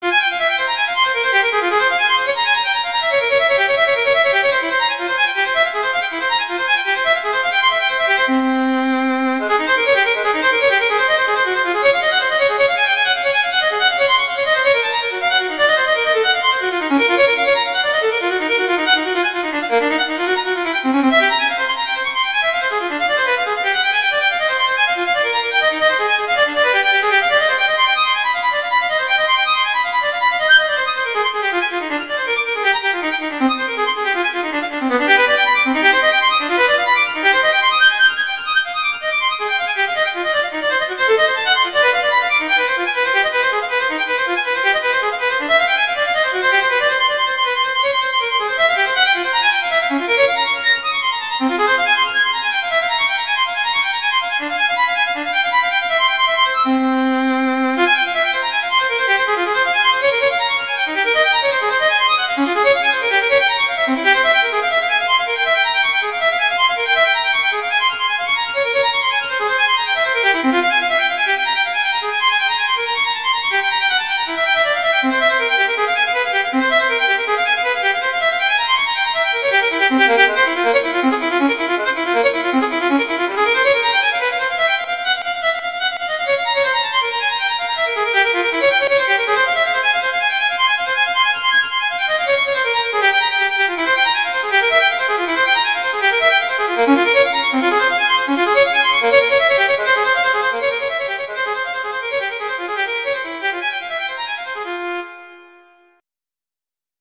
Instrumentation: violin solo
classical, french, instructional